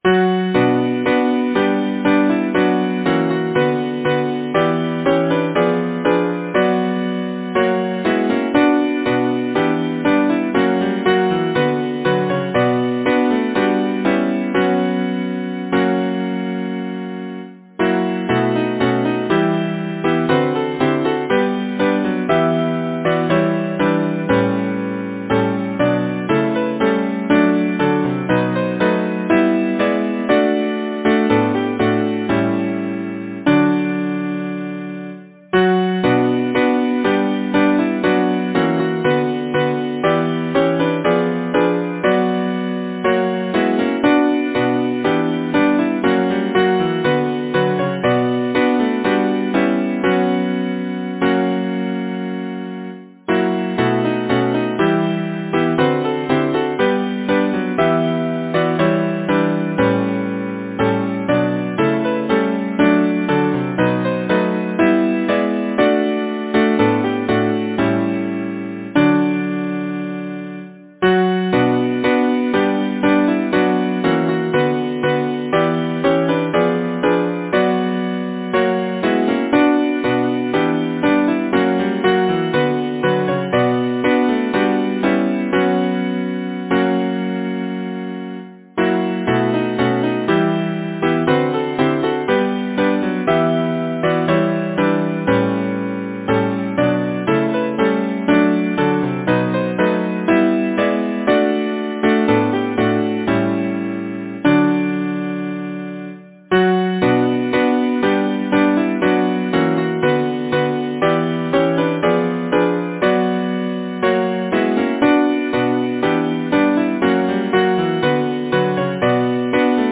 Title: The snows are whirling Composer: Frederic James Lyricist: Augustine Joseph Hickey Duganne Number of voices: 4vv Voicing: SATB Genre: Secular, Partsong
Language: English Instruments: A cappella